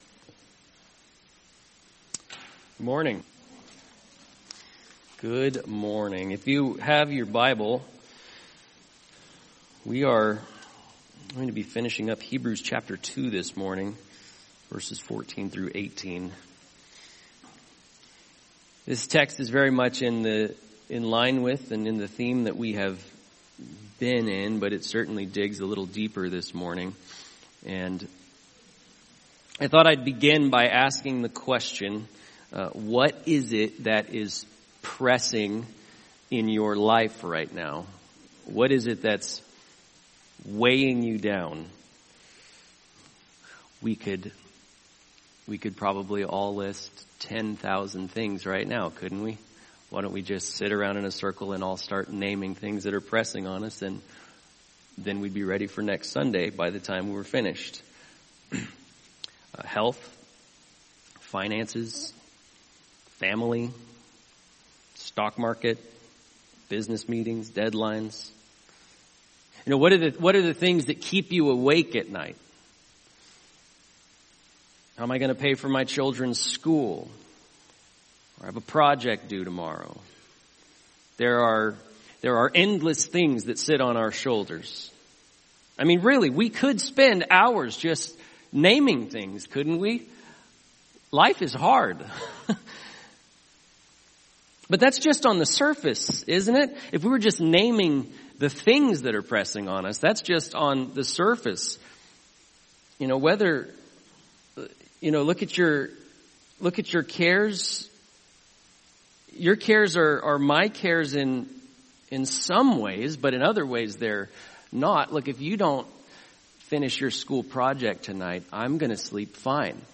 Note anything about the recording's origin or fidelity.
Jesus Is Better Passage: Hebrews 2:14-18 Service: Sunday Morning « Jesus